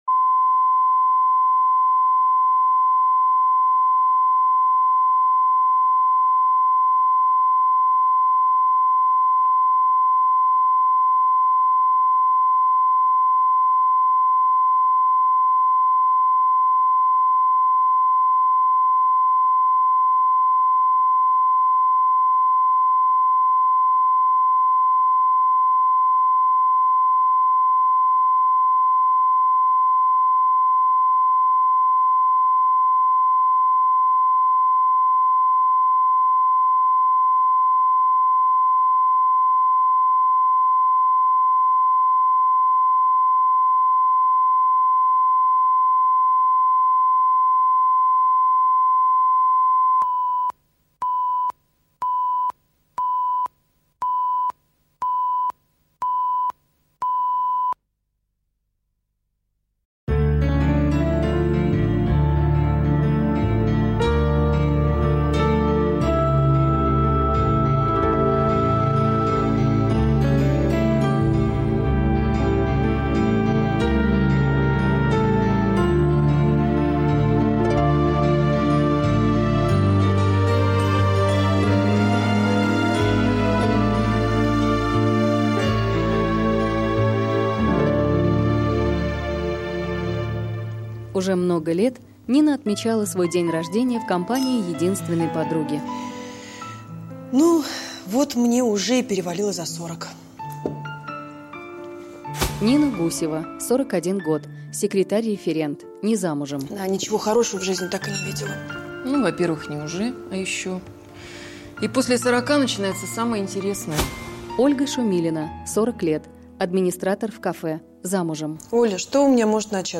Аудиокнига Возраст осени | Библиотека аудиокниг